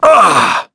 Shakmeh-Vox_Damage_04.wav